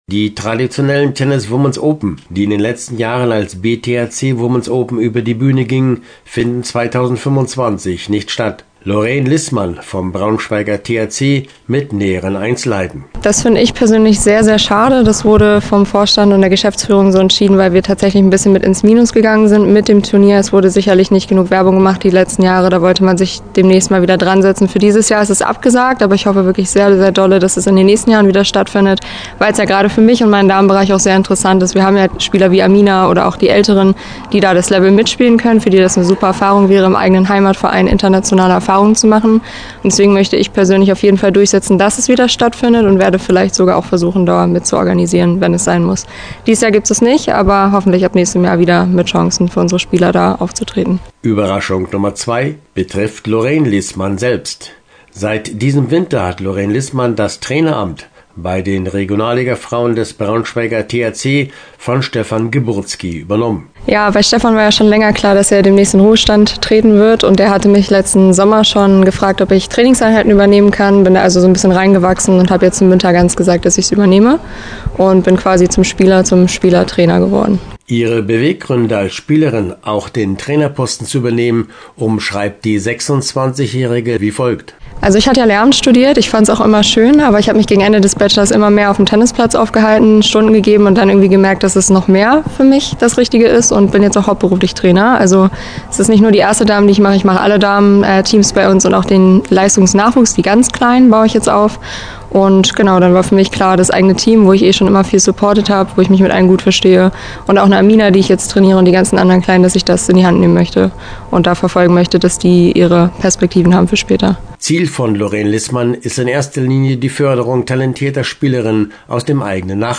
Zum Sport aus regionaler Sicht: In den drei obersten Fußball-Ligen der Männer war an diesem Wochenende Länderspielpause, und bei den Frauen fand das Pokal-Halbfinale statt – leider ohne Rekordsieger VfL Wolfsburg.